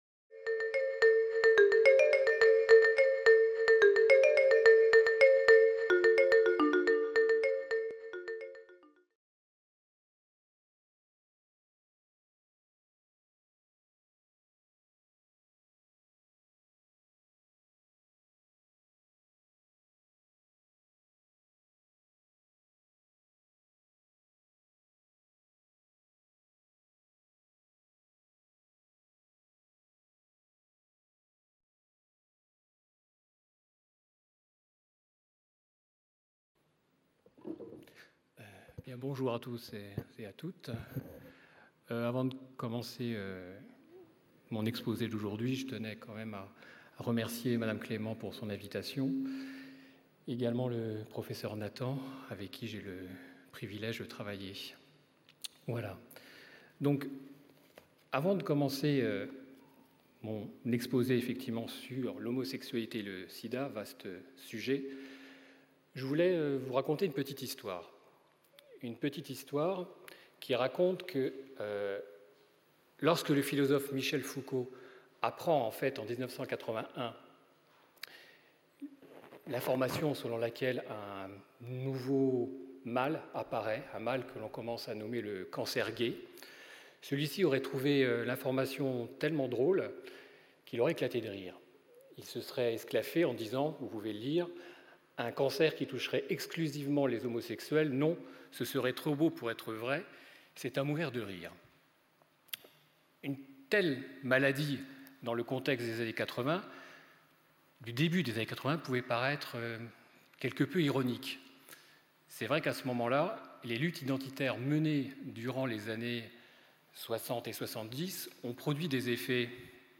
Conférence de l’Université populaire du quai Branly (UPQB), donnée le 11 mai 2016 Ce cycle décrypte des mouvements historiques de révoltes et de combats, pacifiques ou non, qui ont été à l’origine de bouleversements politiques et sociaux, et qui restent, encore aujourd’hui, gravés dans nos cultures et nos imaginaires.